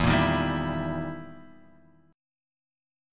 EmM7b5 chord {0 1 1 0 x 0} chord